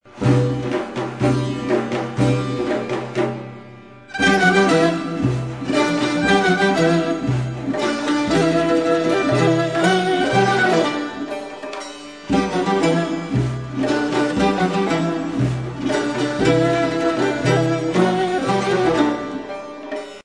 伊朗古典音樂２